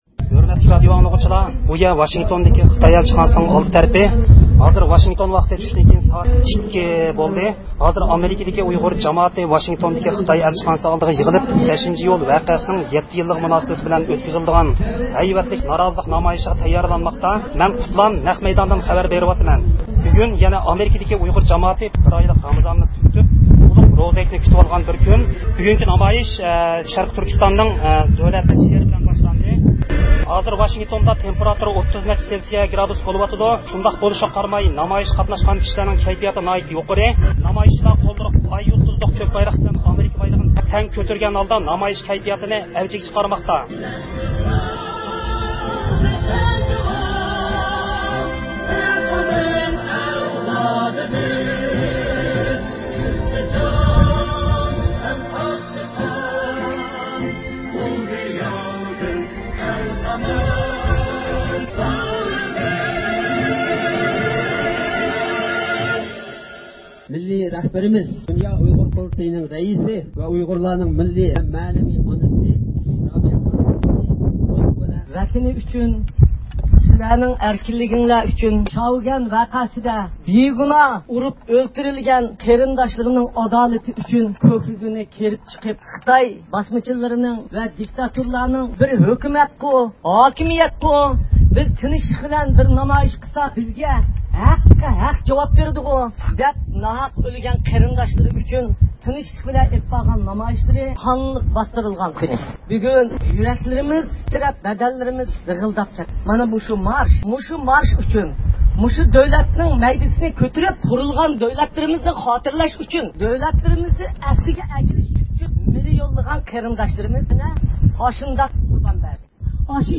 دۇنيا ئۇيغۇر قۇرۇلتىيىنىڭ رەئىسى رابىيە قادىر خانىم شەخسەن ئۆزى نامايىشقا قاتنىشىپ، نۇتۇق سۆزلىدى.